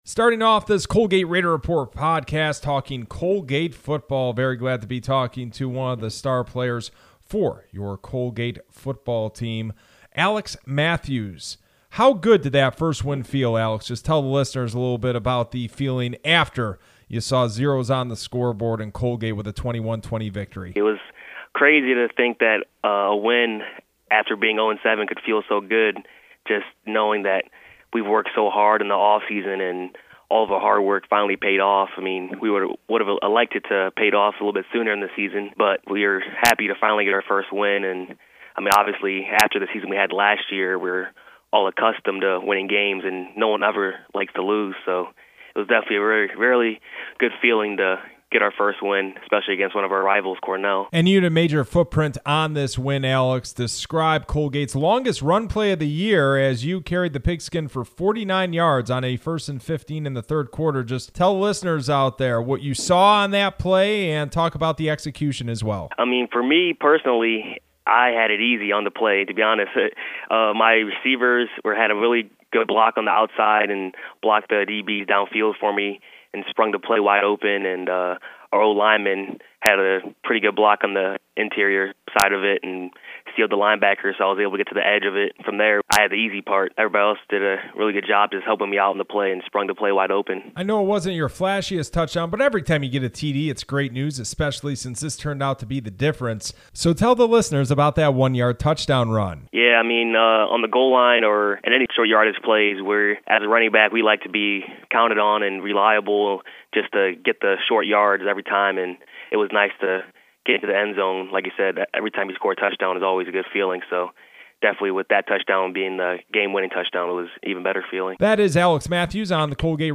Interview
Interview.mp3